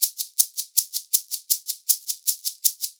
80 SHAK 14.wav